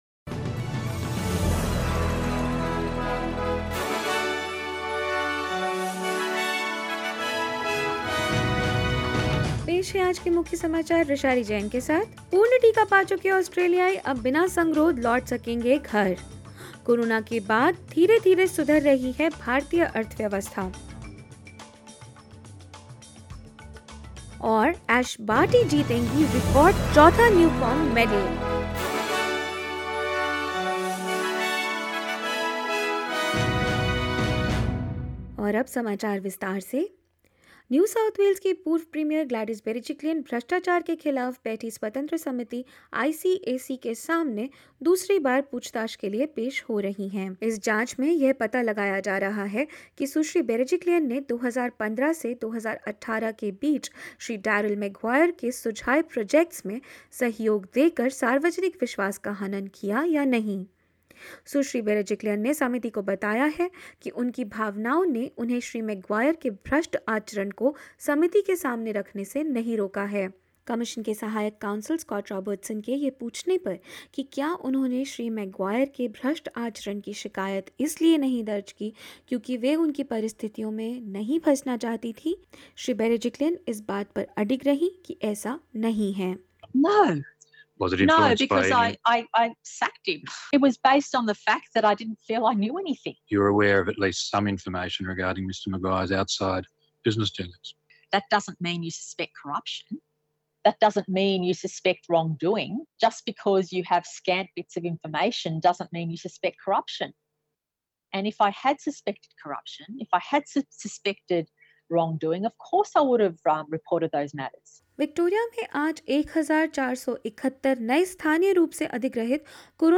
In this latest SBS Hindi News bulletin of Australia and India: Fully vaccinated Australians return home without having to quarantine; Tension between Australia and France escalate overseas and more.